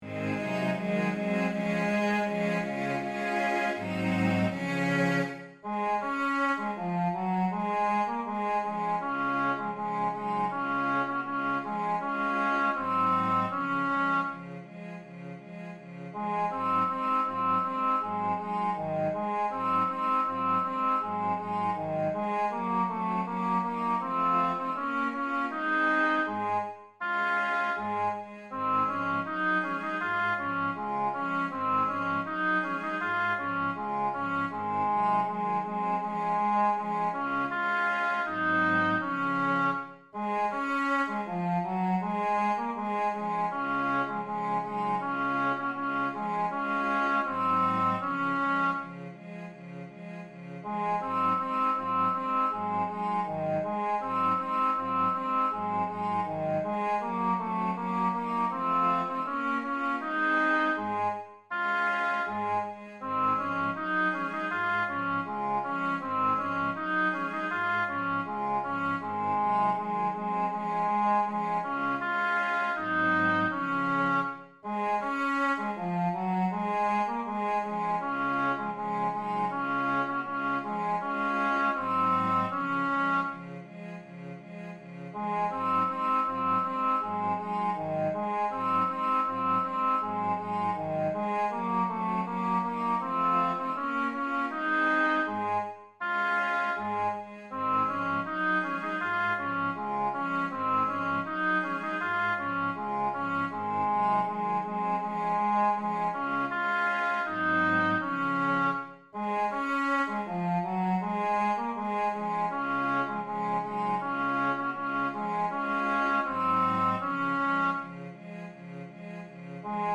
Das Wandern ist des Müllers Lust Tenor 2 als Mp3
das-wandern-ist-des-muellers-lust-ttbb-zoellner-einstudierung-tenor-2.mp3